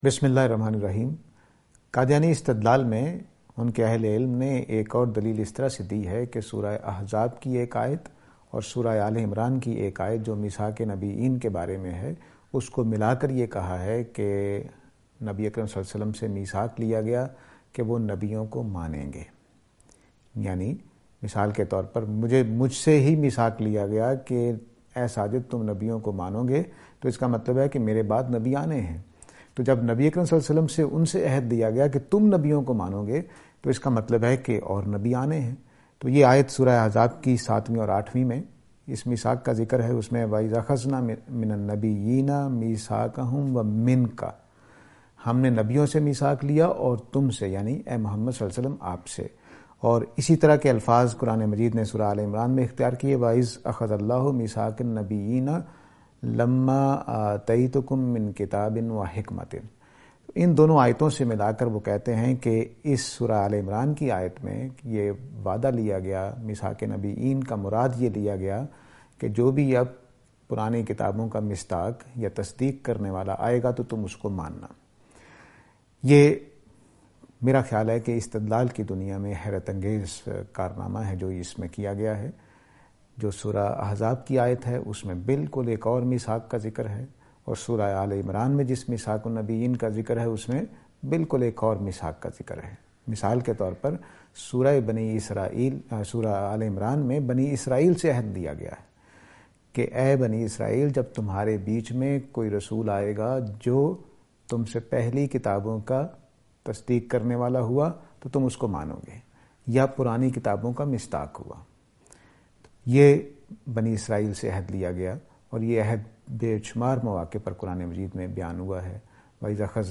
This lecture series will deal with Reviewing Qadiyani Discourse .